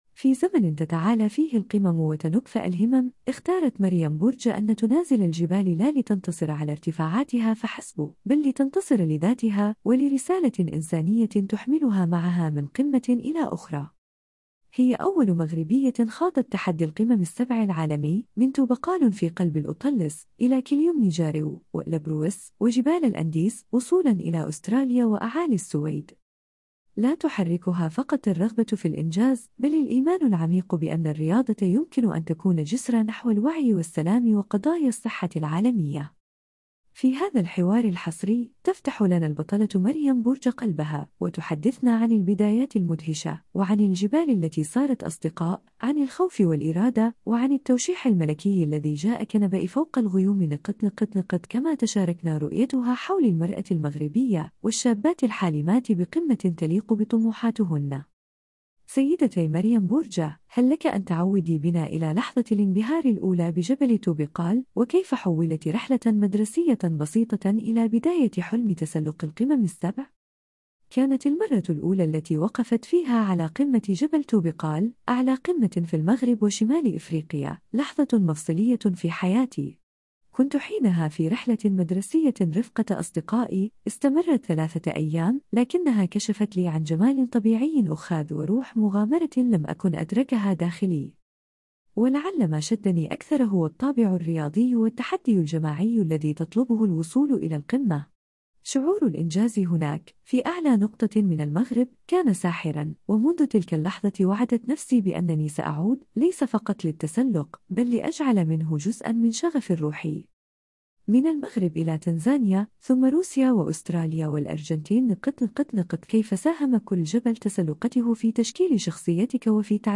في هذا الحوار الحصري